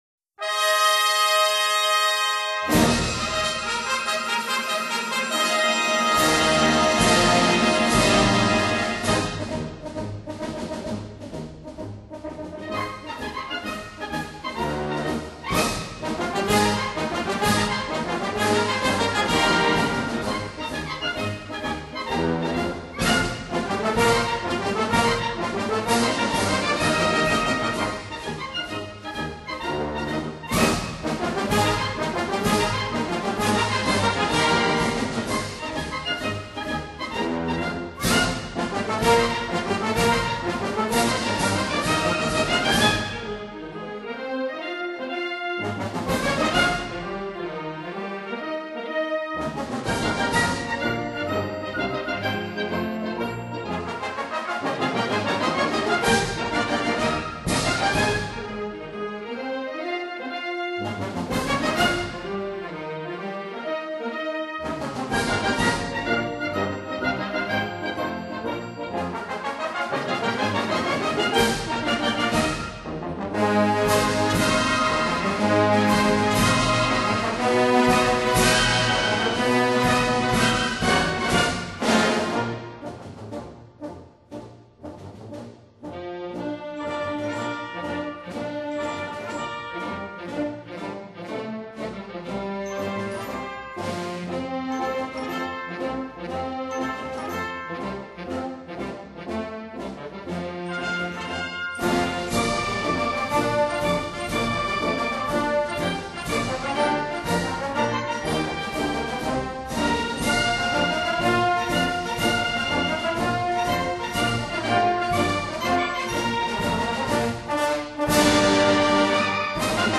铜管乐合奏音响丰满、节奏铿锵、鼓舞人心，用于公共场合常能充分展示一个国家的国威与军威。